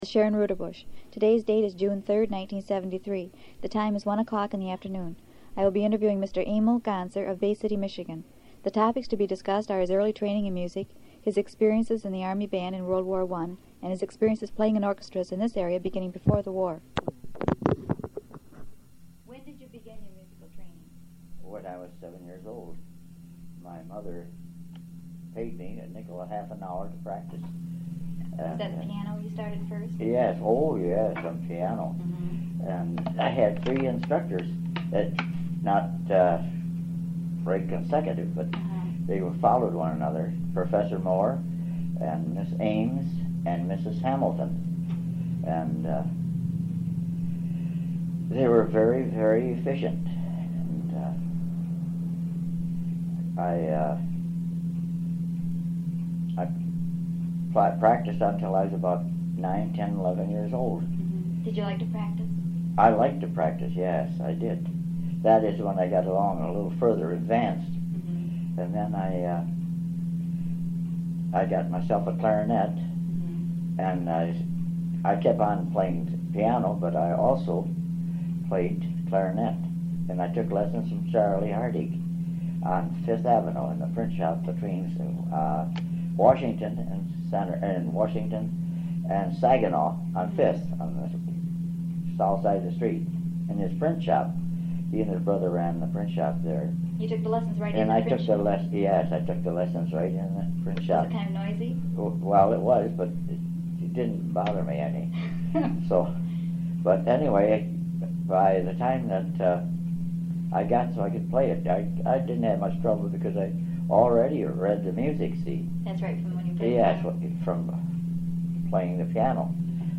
Oral History
Original Format Audiocassette